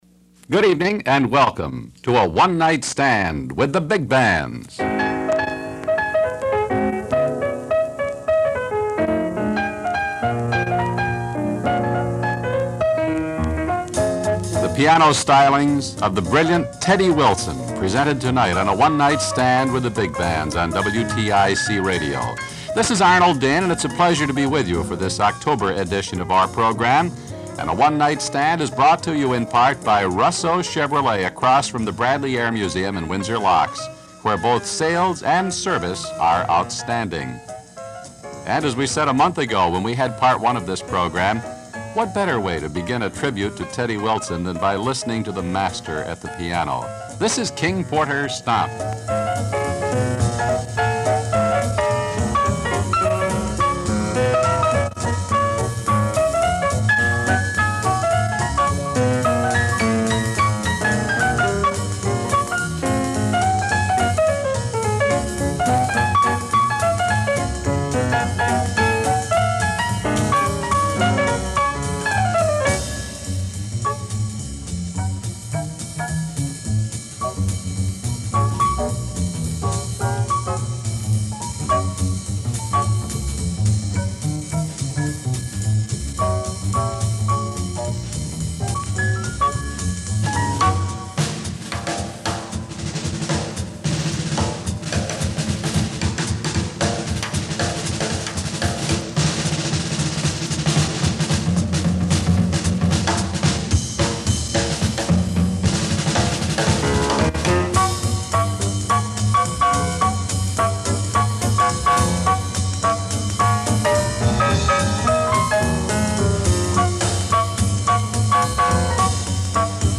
(As originally broadcast on WTIC, Hartford, CT)